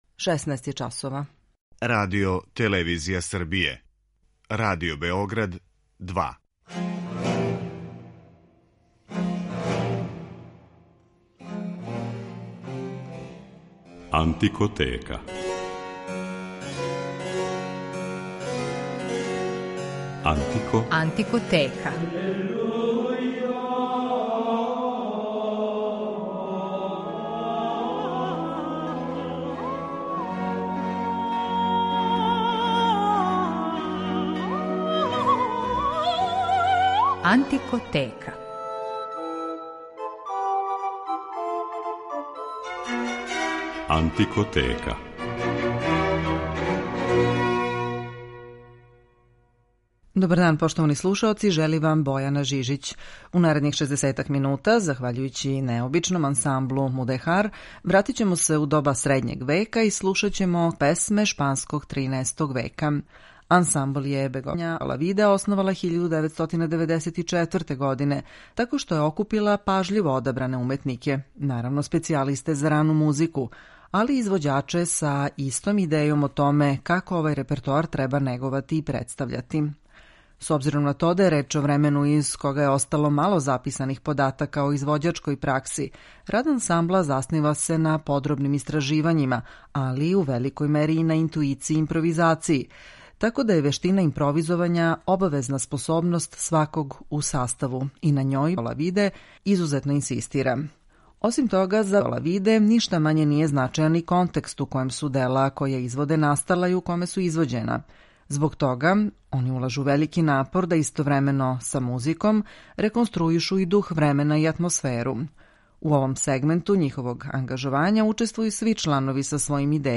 Захваљујући необичном ансмблу Мудехар у данашњој емисији ћемо се вратити у доба средњег века и слушаћемо музику шпанског 13. столећа.